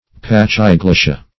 Search Result for " pachyglossia" : The Collaborative International Dictionary of English v.0.48: pachyglossia \pach`y*glos"si*a\ (p[a^]k`[i^]*gl[o^]s"s[i^]*[.a]), n. [Pachy- + Gr. glw^ssa tongue.]